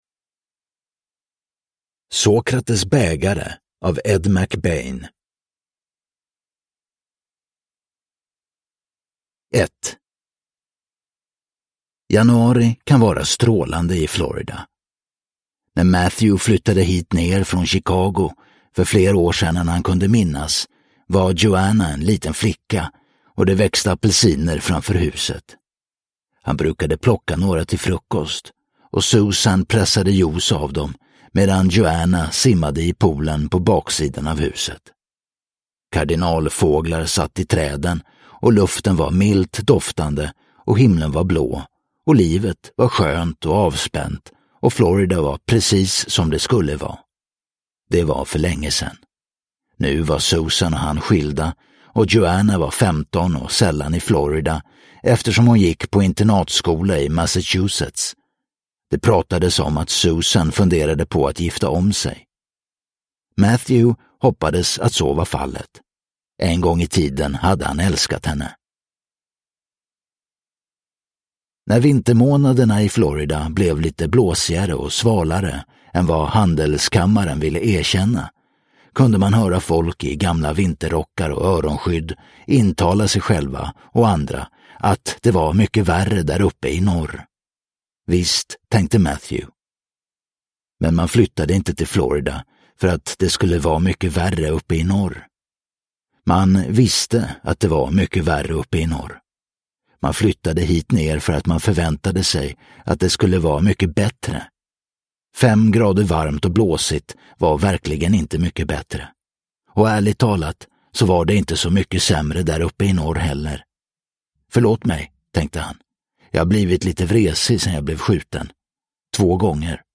Sokrates bägare – Ljudbok – Laddas ner